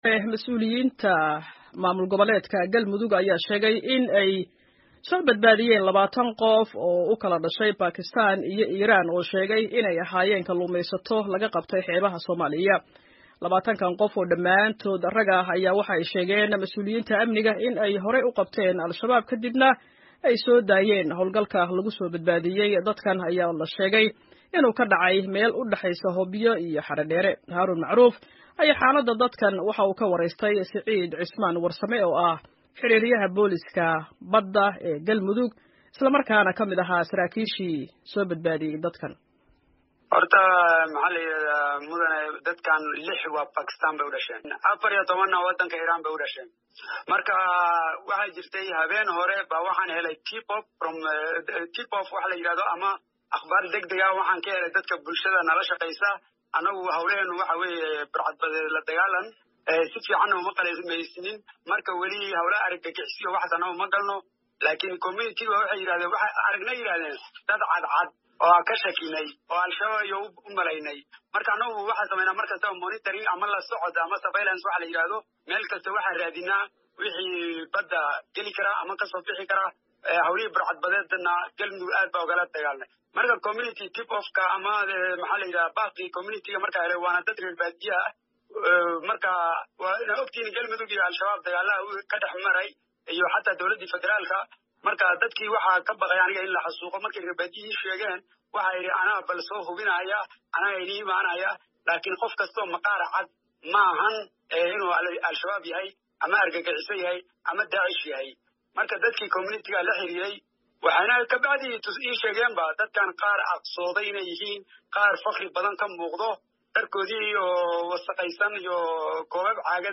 ayaa xaaladda dadkan ka waraystay